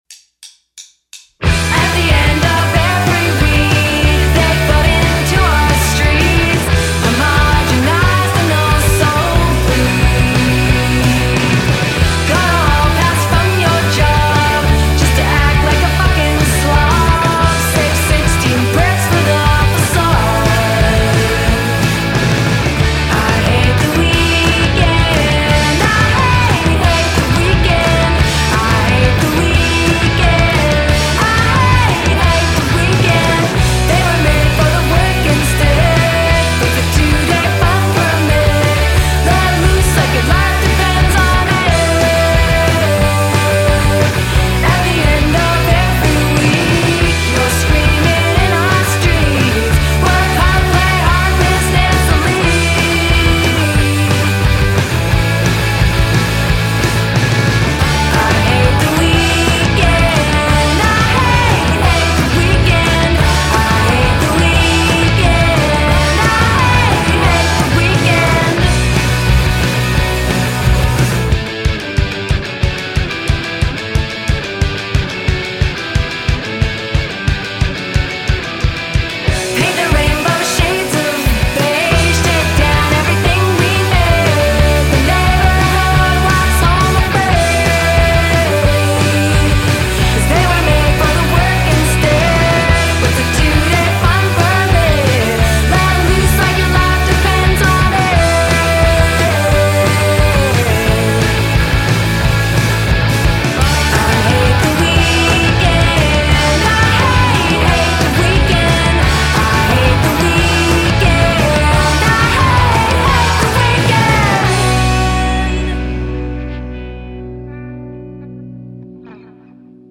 wide and expansive, bringing a fullness to the band’s sound